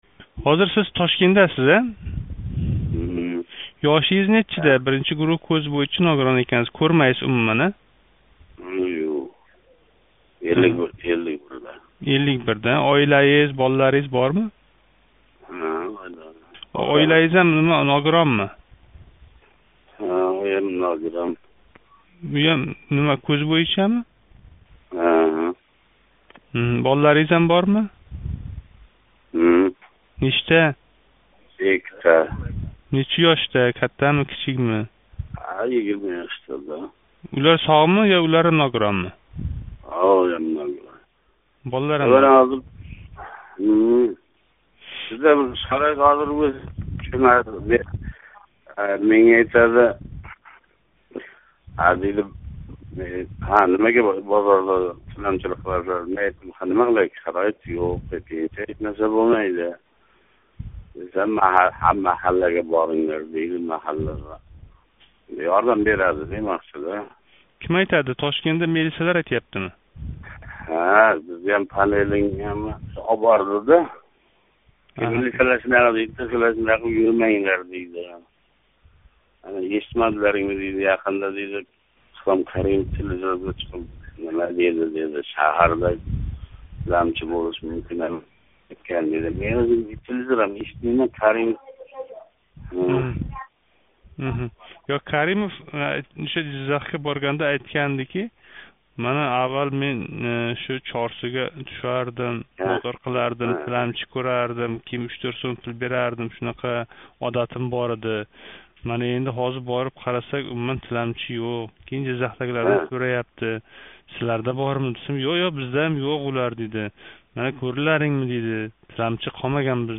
Сурхондарёлик 51 ёшли кўзи ожиз тиланчи билан суҳбат: